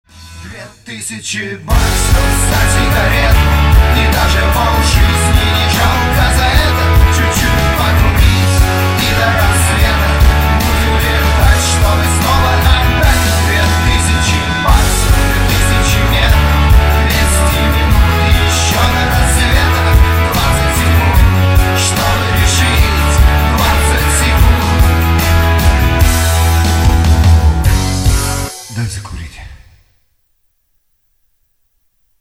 мужской вокал
душевные
грустные
печальные
русский рок